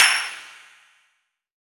clapAce.wav